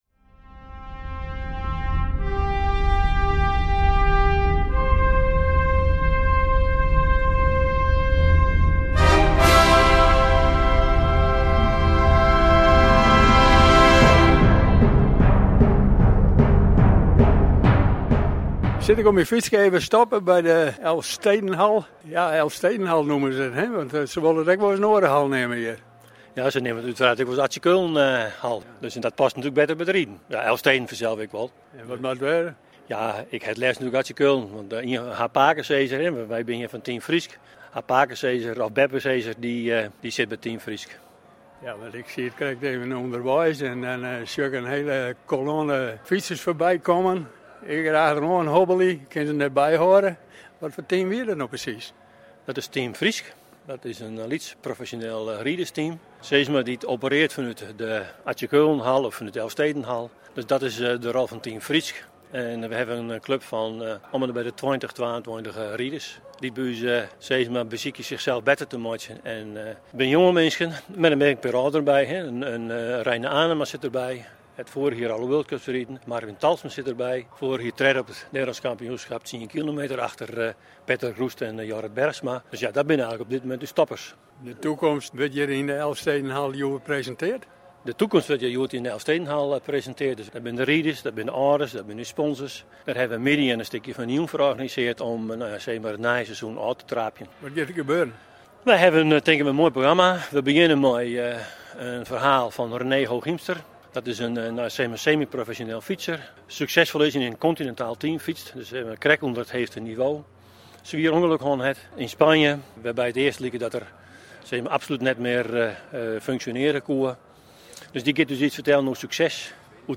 Schaatsploeg Team FrySk bestaat voor het seizoen 2020-2021 uit 20 schaatsers. Vrijdagmiddag was de teampresentatie en werden de contracten getekend in de Leeuwarder Elfstedenhal.